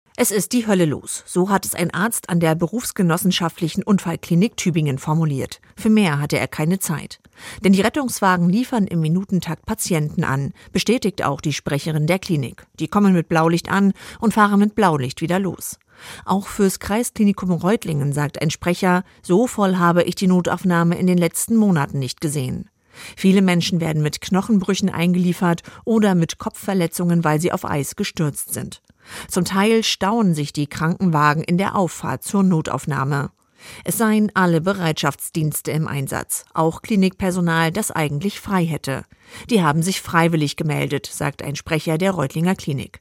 Nachrichten Glatteis und überfüllte Notaufnahmen